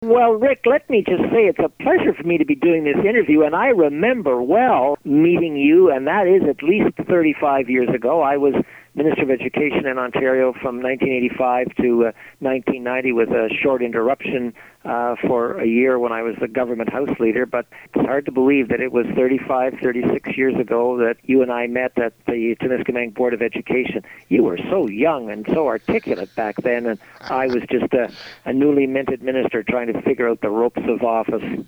Sean Conway Order of Ontario interview